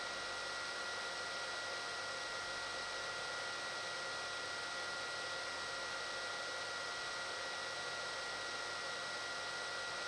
DJI Mavic Pro Lautstärke vom Motor
Die Reisedrohne ist verhältnismäßig leise.
Die Aufnahmen wurden mit einem hochsensiblen Großmembranmikrofon (dem AnzeigeRode Podcaster) aufgenommen. Also nicht wundern, wenn es etwas lauter klingt da wirklich alles mit aufgenommen wurde.
So hört sich die Drohne an, wenn sie angeschaltet wurde aber die Rotorblätter nicht rotieren. Zu hören ist hier vor allem die Lüftung der Mavic Drohne:
DJI-Mavic-Pro-Lautstaerke.m4a